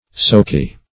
Soaky \Soak"y\, a.
soaky.mp3